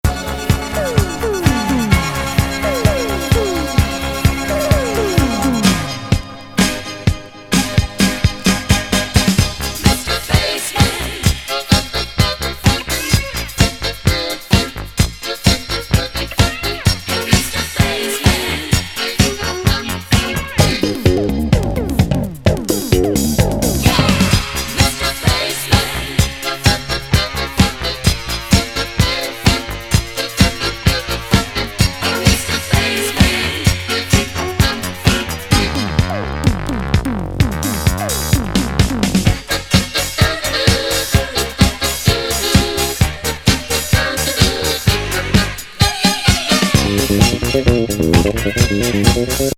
まどろみのメロウ・